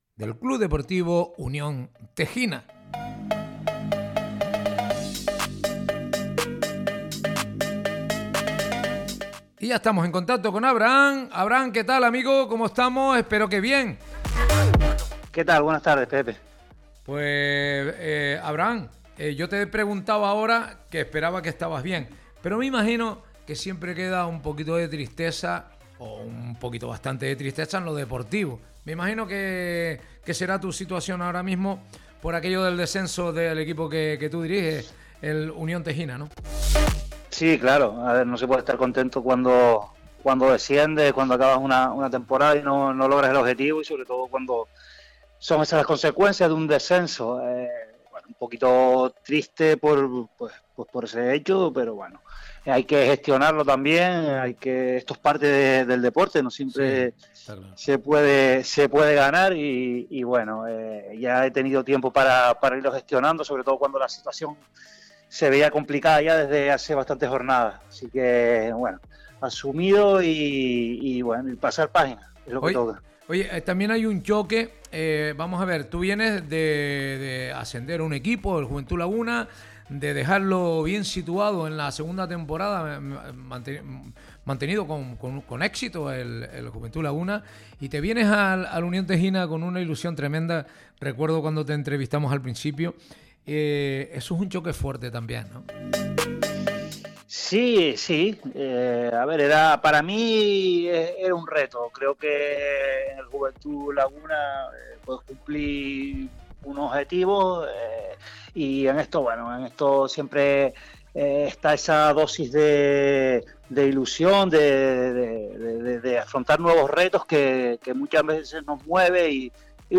La entrevista la puedes escuchar por nuestra emisora online, si la coges iniciada no te preocupes, lo puedes volver a hacer cada 33 minutos.